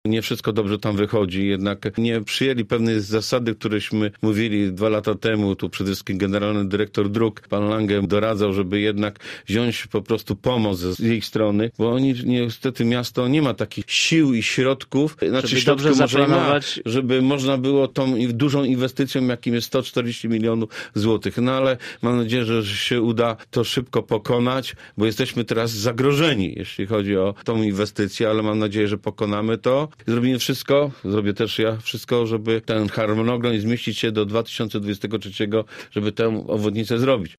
Nie wszystko dobrze tam wychodzi – mówi o obwodnicy południowej Zielonej Góry poseł Jerzy Materna z Prawa i Sprawiedliwości.